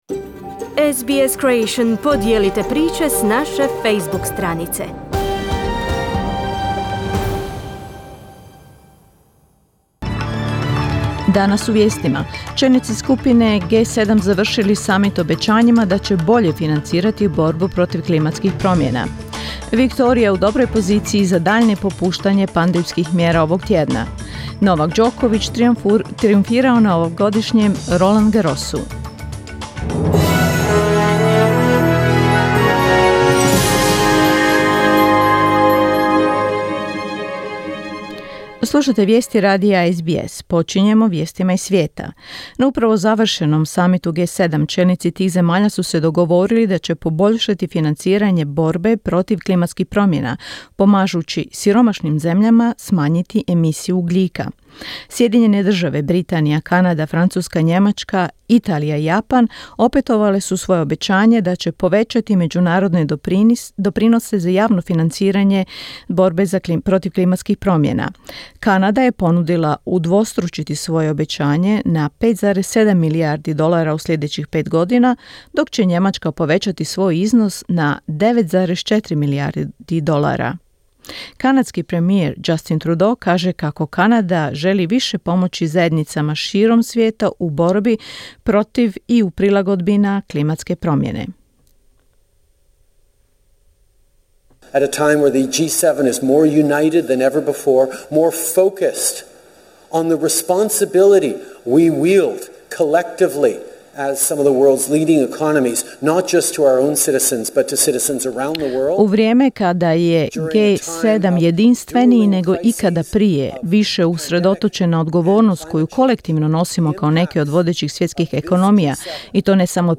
Vijesti radija SBS na hrvatskom jeziku.